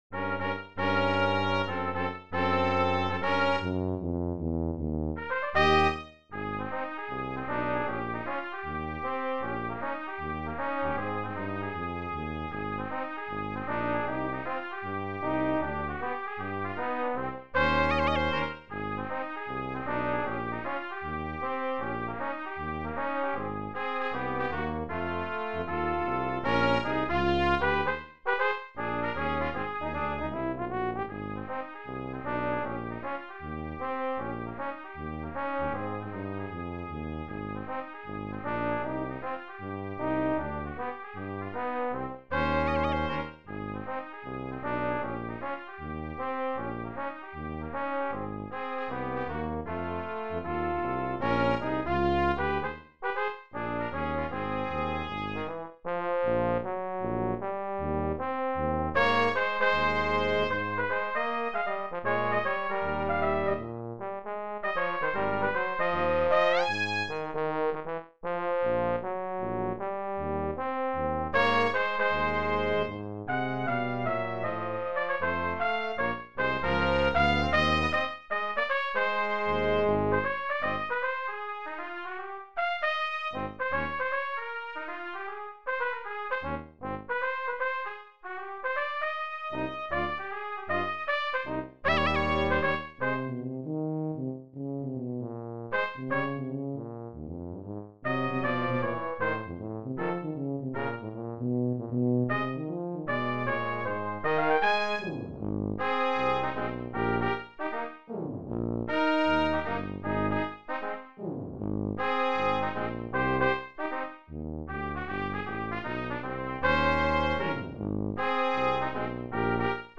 Brass Trio TTT